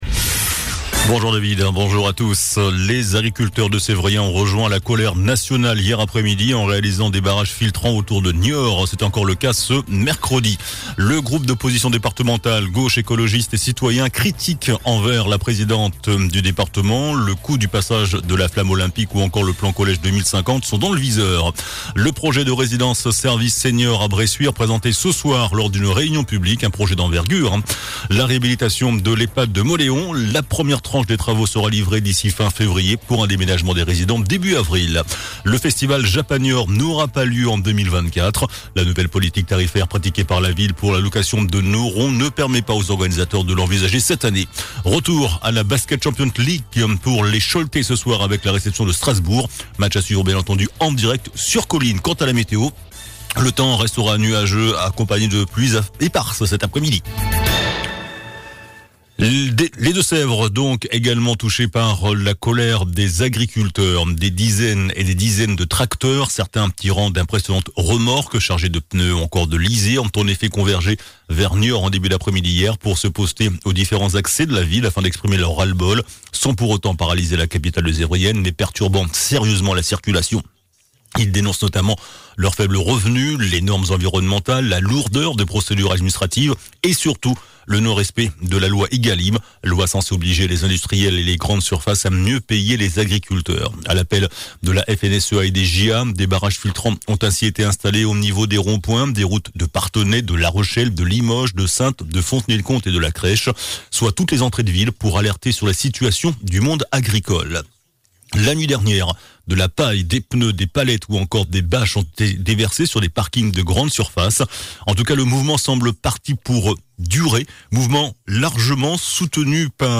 JOURNAL DU MERCREDI 24 JANVIER ( MIDI )